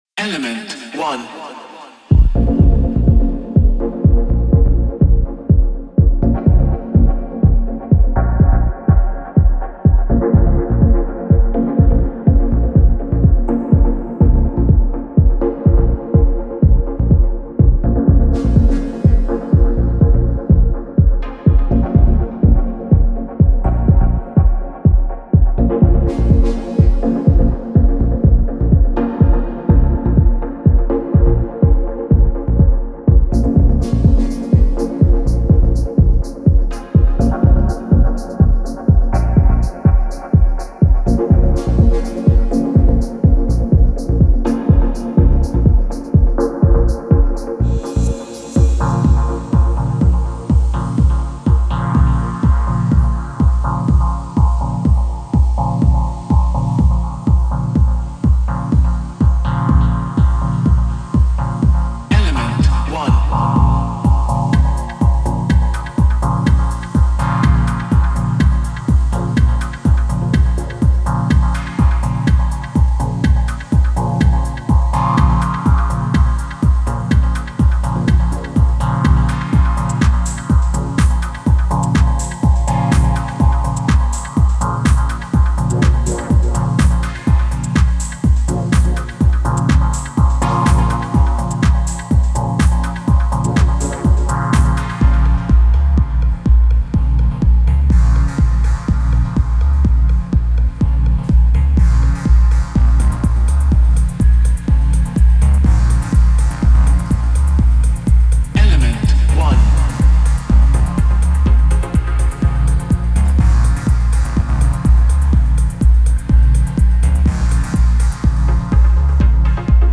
深くアナログなベースラインやサブを豊かに含む低域の質感で、温かみとグルーヴを加えます。
それは豊かで、霞がかり、絶えず変化し続ける響きです。
すべてのループは温かみとディレイ、リバーブで満たされ、独特の空間的サウンドを生み出します。
Genre:Dub Techno